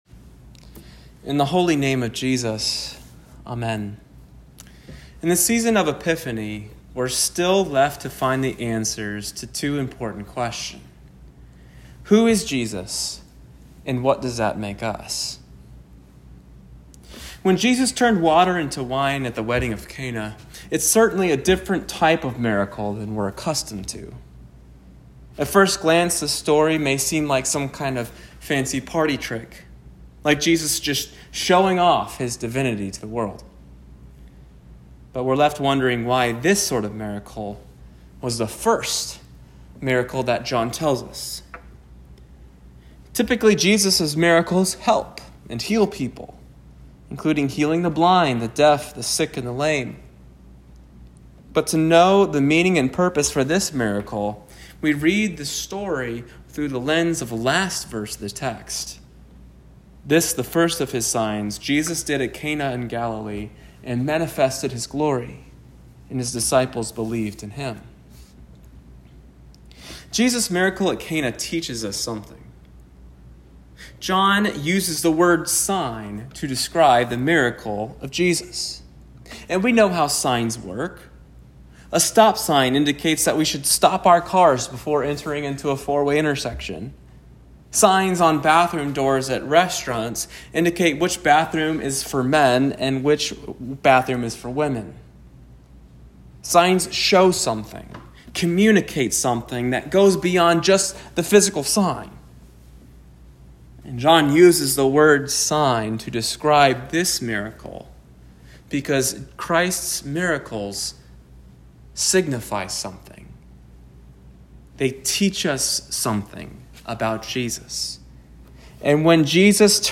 Second Sunday after Epiphany Text: John 2:1-11
Recent Sermons